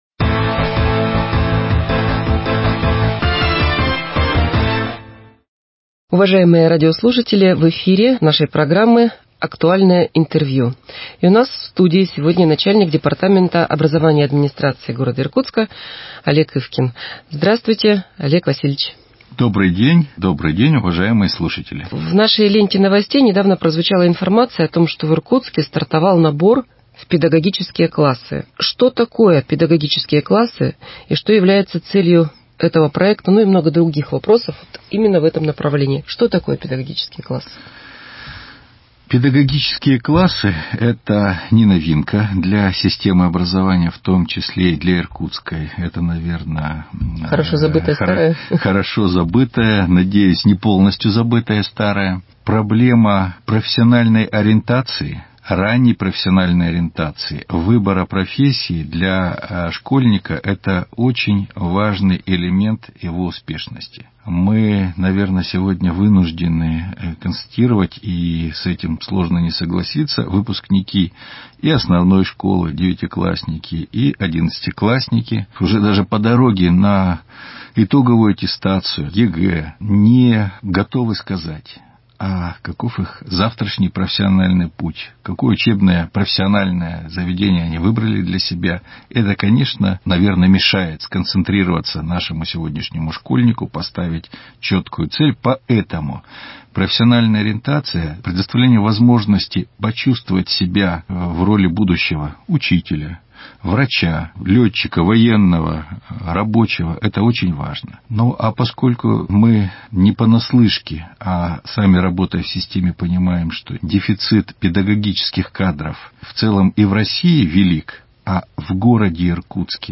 Актуальное интервью: Педагогические классы в Иркутске 09.06.2021